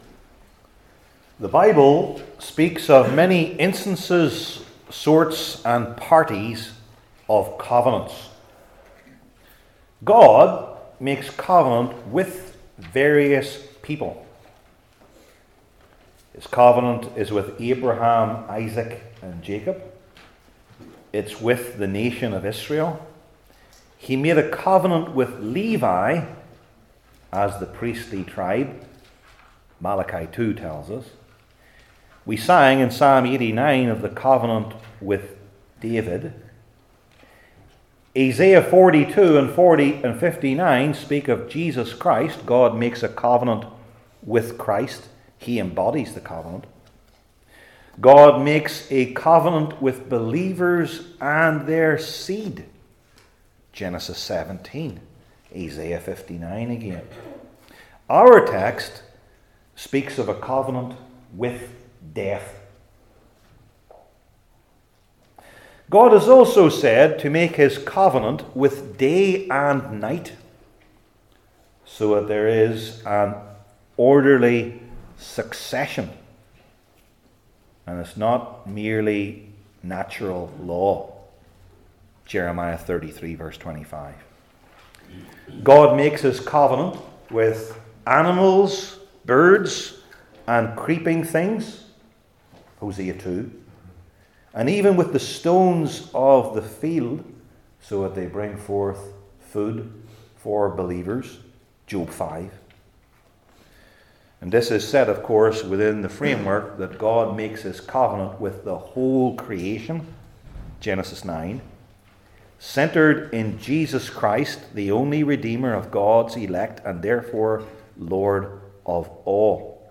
Old Testament Sermon Series I. The Meaning of the Covenant With Death II.